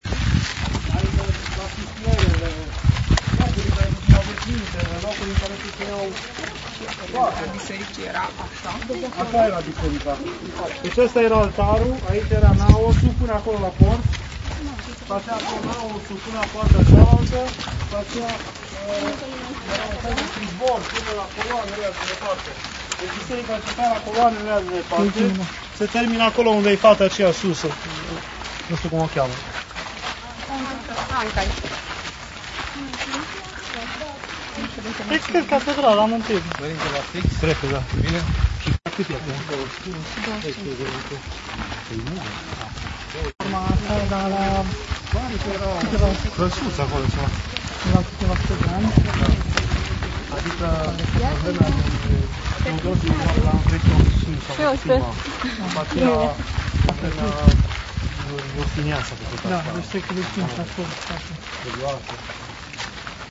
Pelerinaj 2 Turcia, Grecia